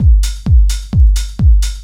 NRG 4 On The Floor 034.wav